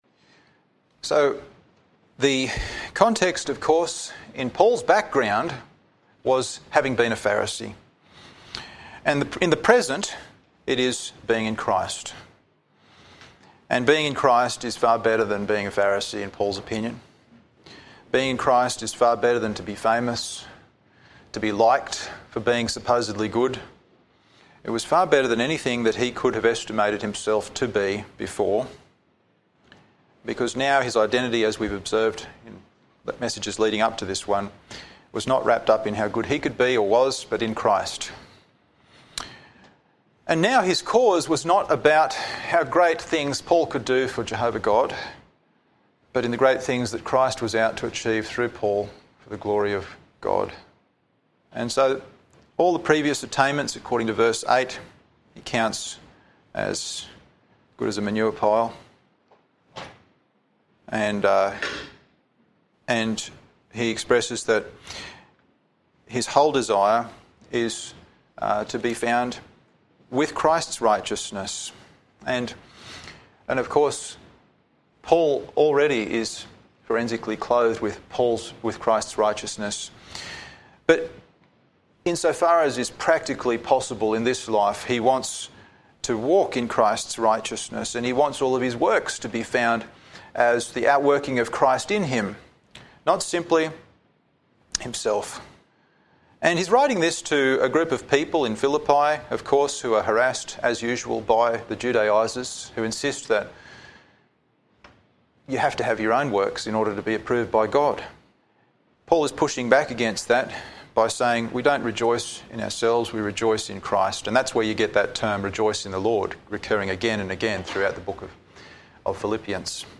Sermons | TBBC | Tamworth Bible Baptist Church
Service Type: Sunday Evening